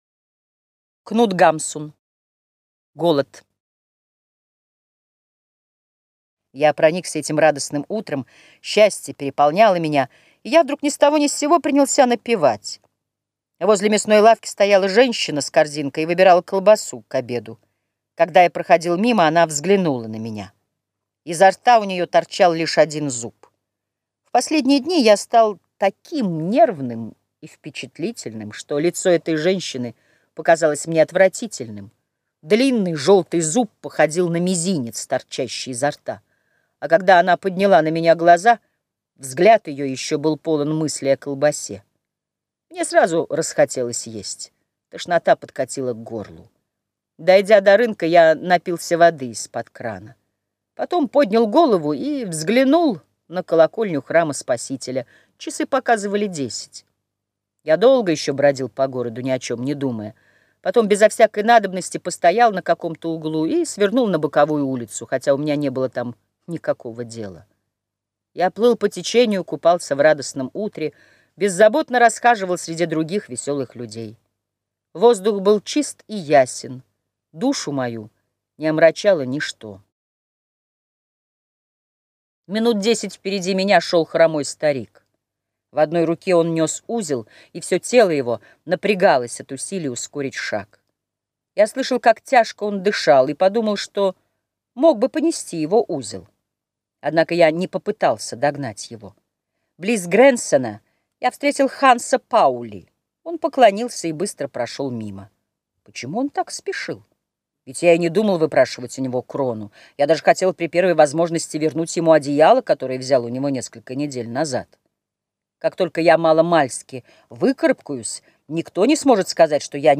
Аудиокнига Голод | Библиотека аудиокниг
Читает аудиокнигу